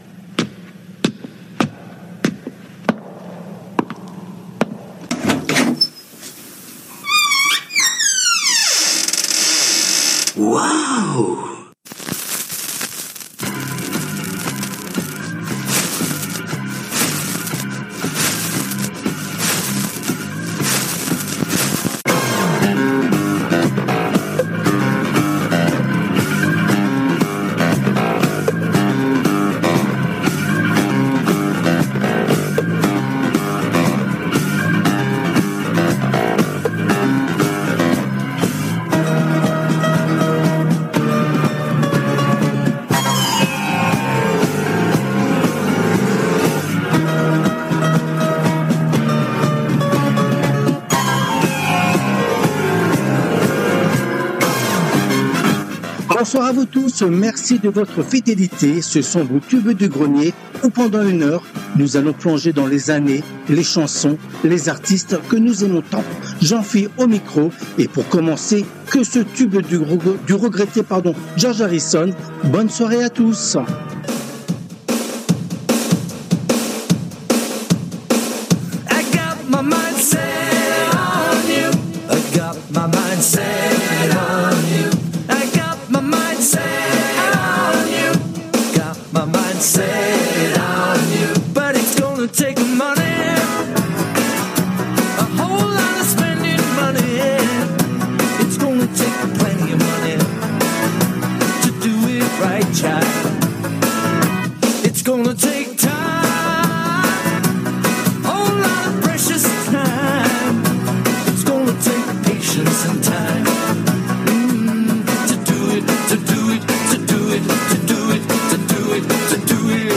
Les Tubes connus ou oubliés des 60's, 70's et 80's